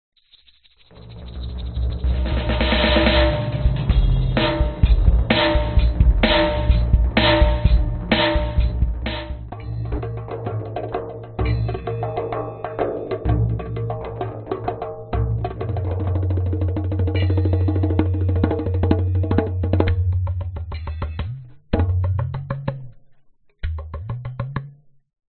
描述：不同的打击乐录音